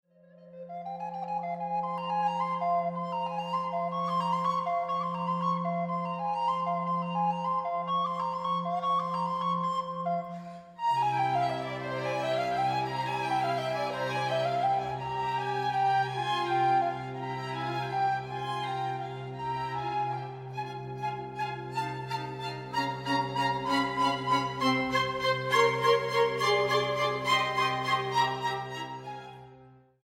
para flauta, cuerdas y contínuo